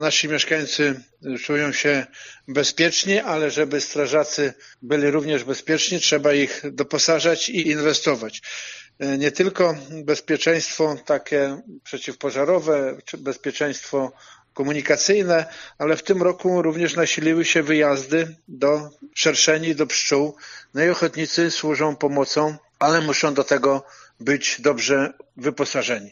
Wójt dodaje, że ochotnicy służą bezpieczeństwu społeczeństwa: